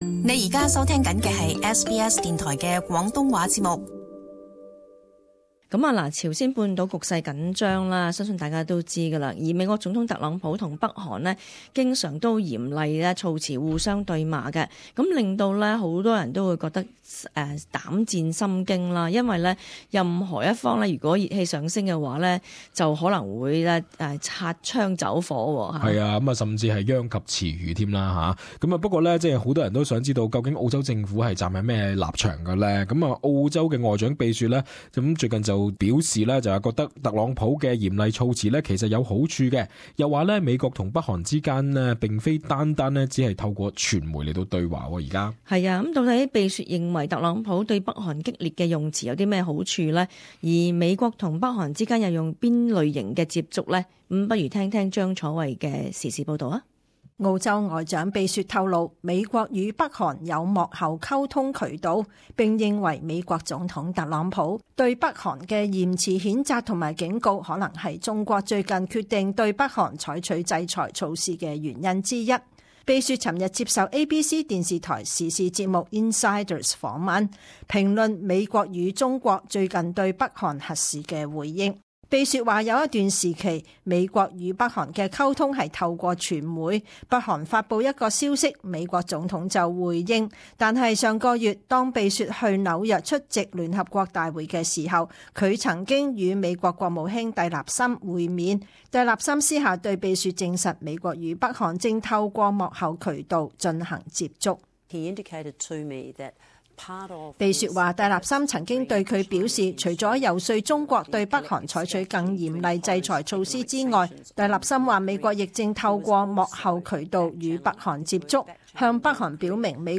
【时事报导】庇雪认同特朗普严辞责斥北韩
SBS广东话播客